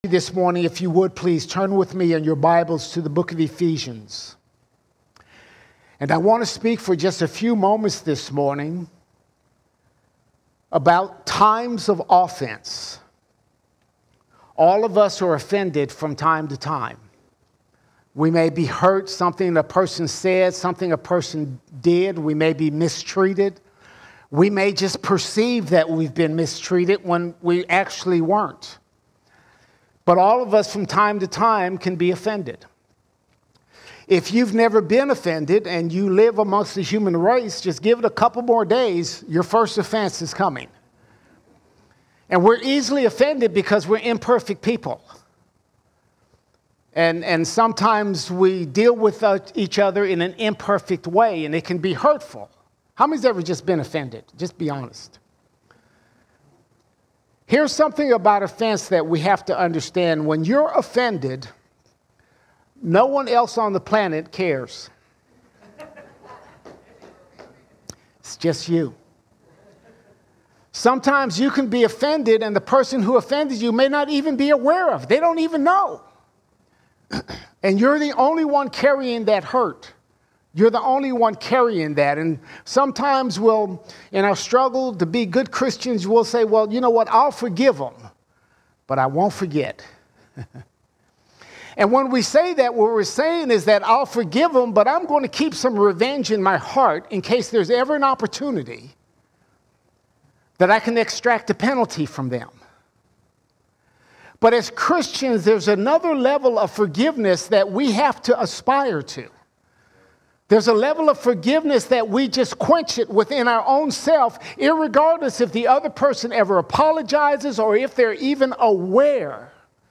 18 December 2023 Series: Sunday Sermons Topic: fruit of the Spirit All Sermons How To Handle Offenses How To Handle Offenses When someone offends and hurts you, how do you respond?